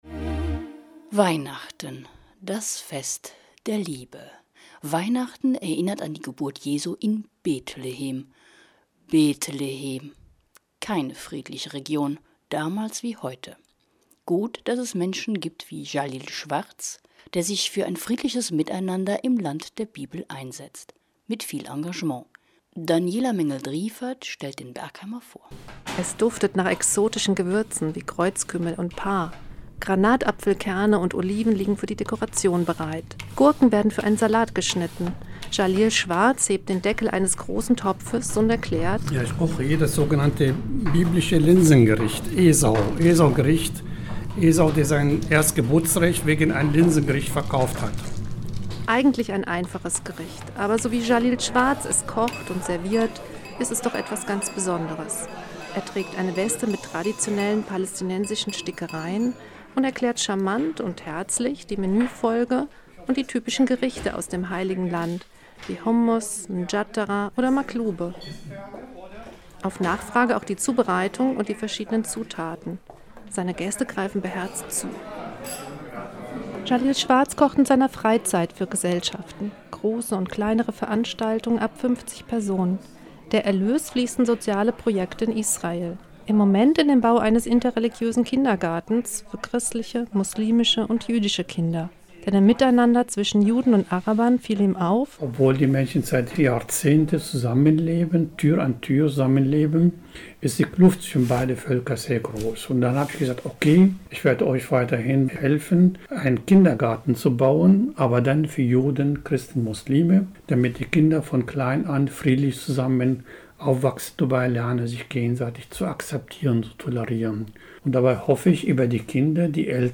Radio-Interviews